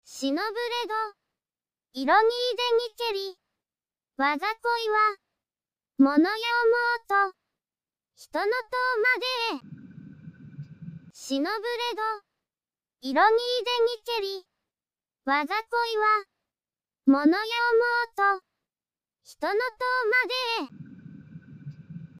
百人一首をさとうささらに詠んでもらった。
一首ごとに２回、ゆっくりめに詠んでもらって、それを全て mp3 にしてみた。